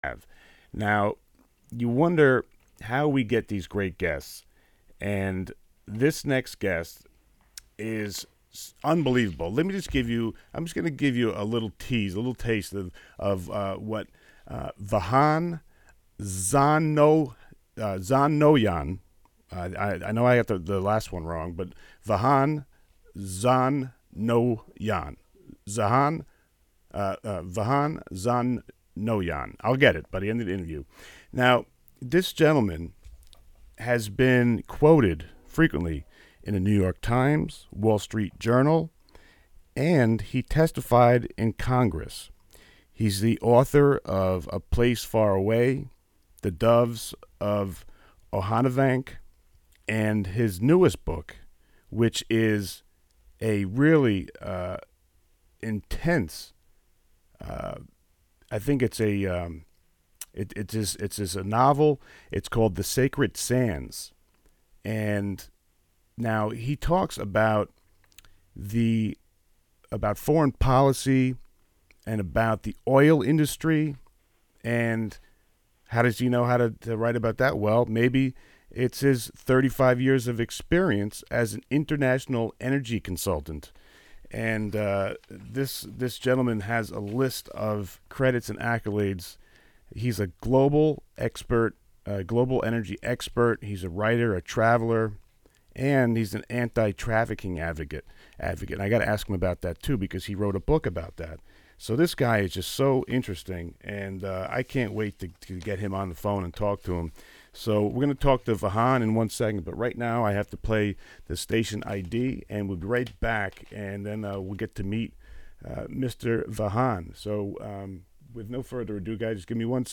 Interview with World’s Most Amazing People